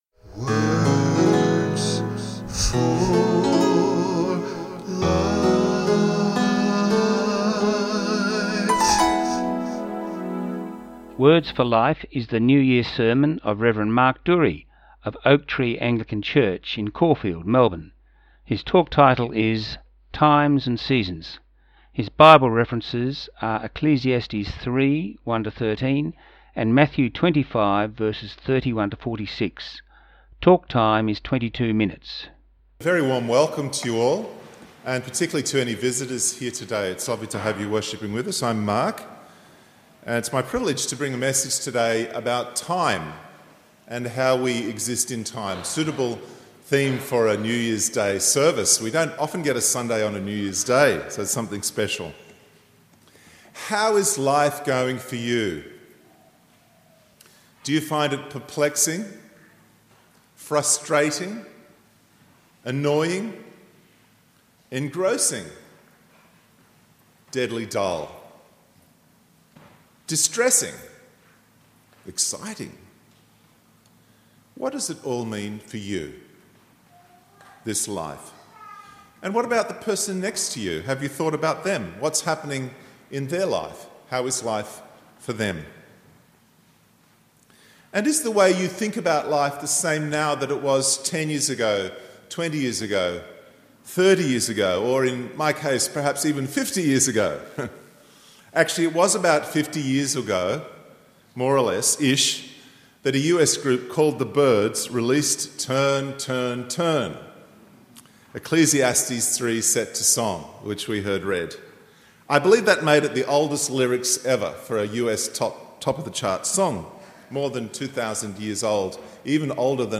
Listen to the Words for Life broadcast of 8Jan17.
His talk was his new year talk and the title was “Times and seasons”.